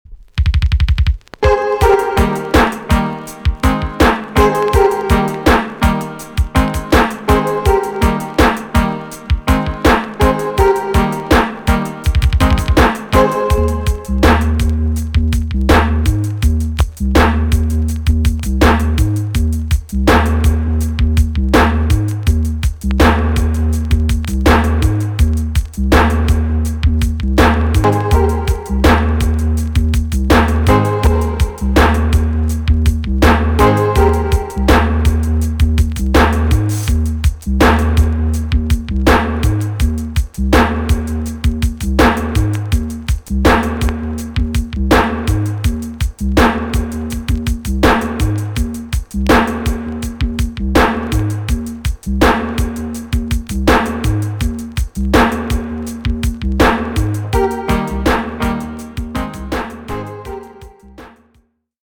TOP >80'S 90'S DANCEHALL
B.SIDE Version
EX- 音はキレイです。